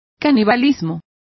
Complete with pronunciation of the translation of cannibalism.